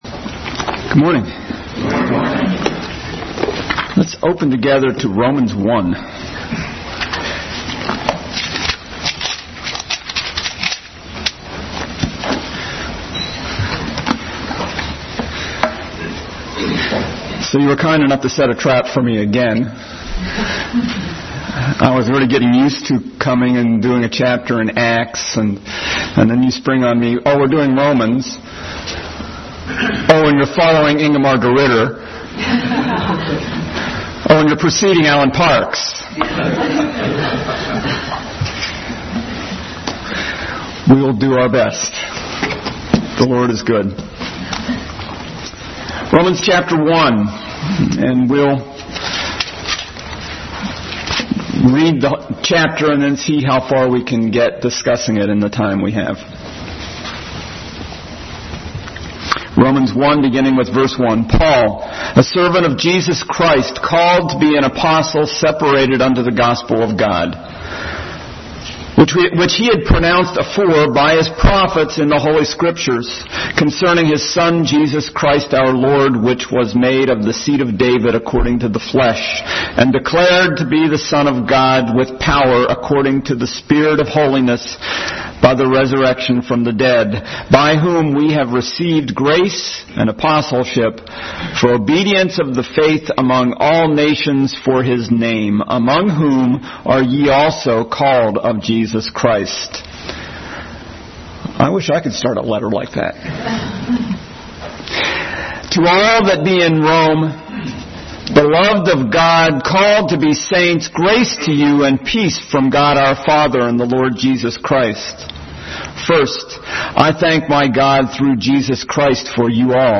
Bible Text: Romans 1:1-20, 1 Corinthians 15:3-4, Habakkuk 2:4 | Adult Sunday School Class. Continued study of the book of Romans.